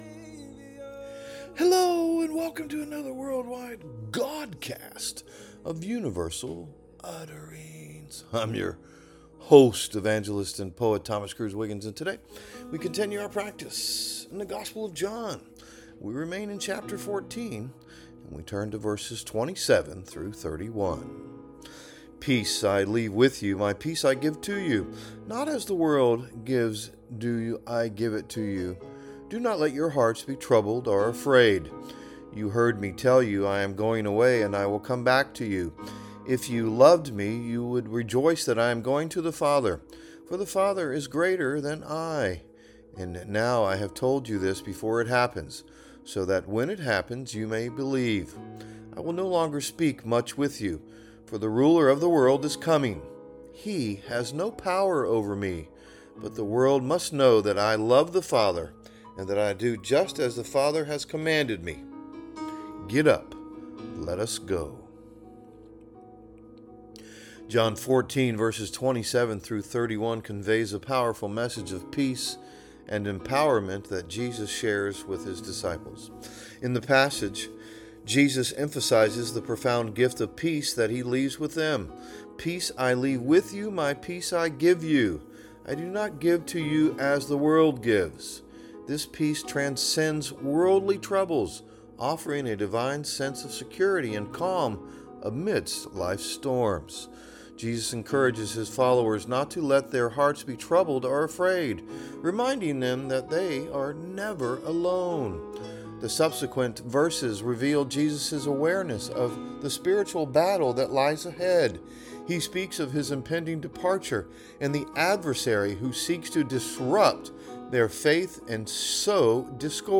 A Godcast